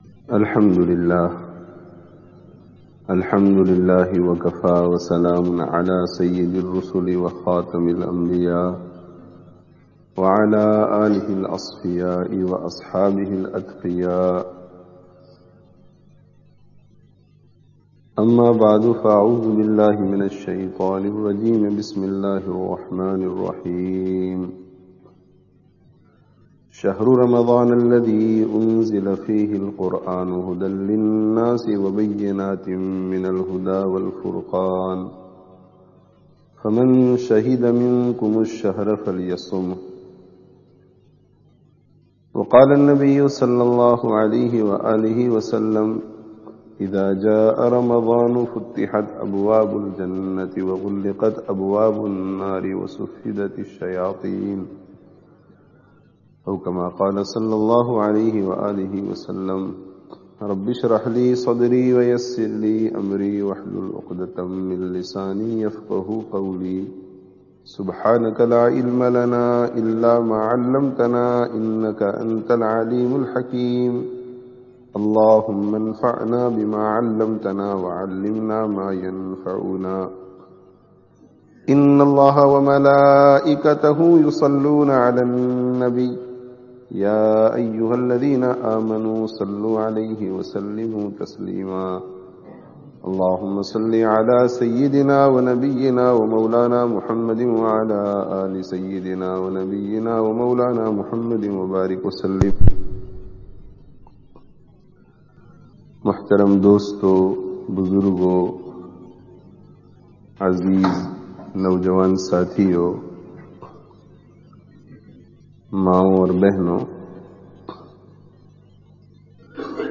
Mā Bāp kī awr Ramadhān kī Qadar (Masjid An Noor, Leicester 15/09/06)